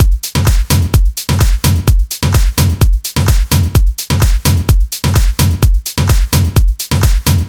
VDE 128BPM Notice Drums 1.wav